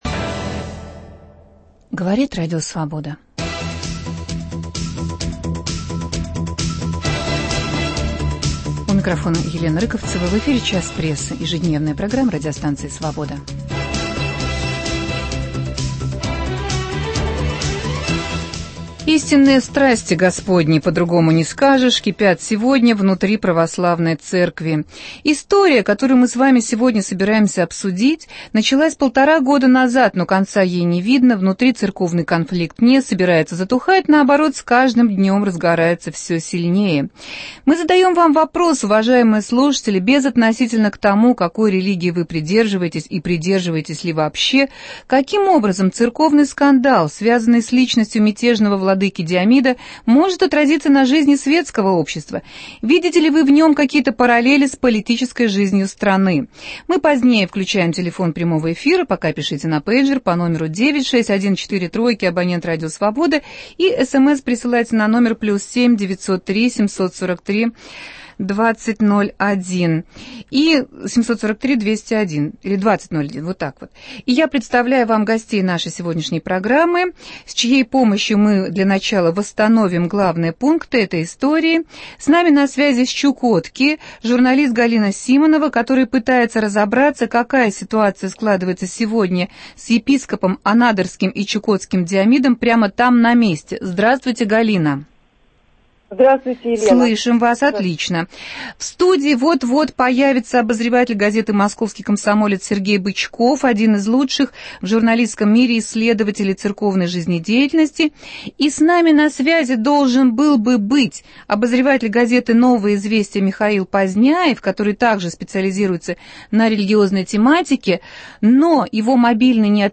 (по телефону с Чукотки)